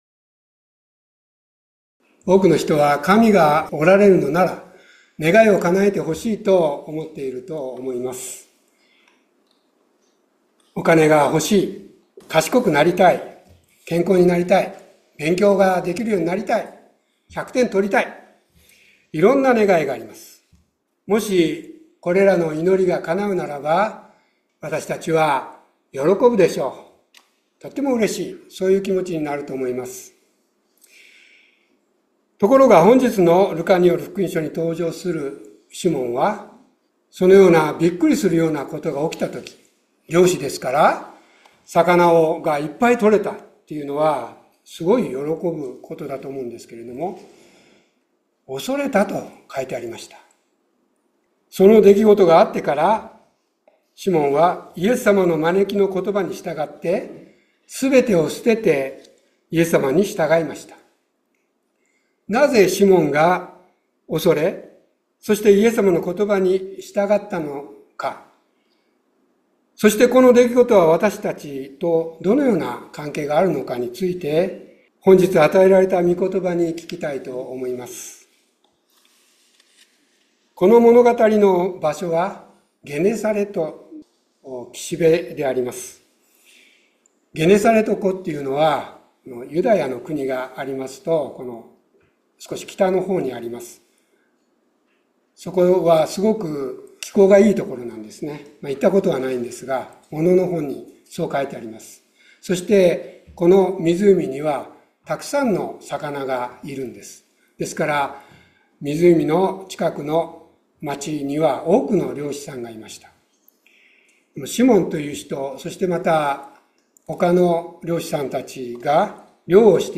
2月9日礼拝説教「困難に踏み出す」